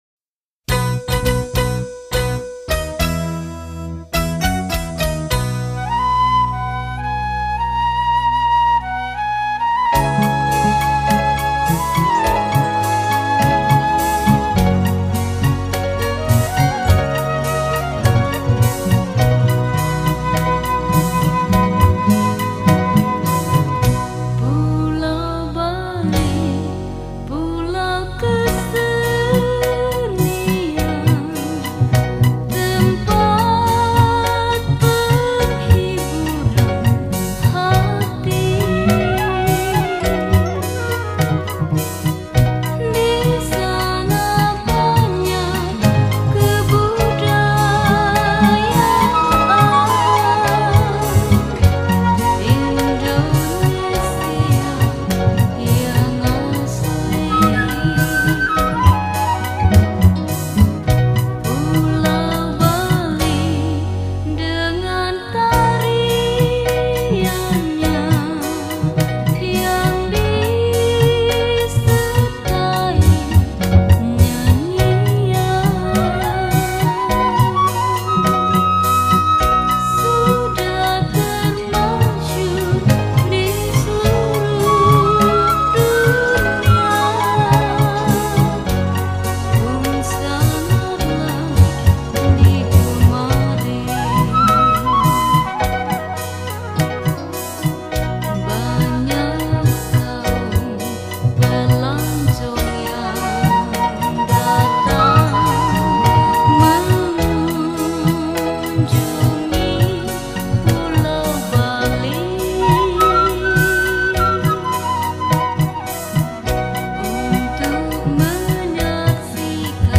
Keroncong Asli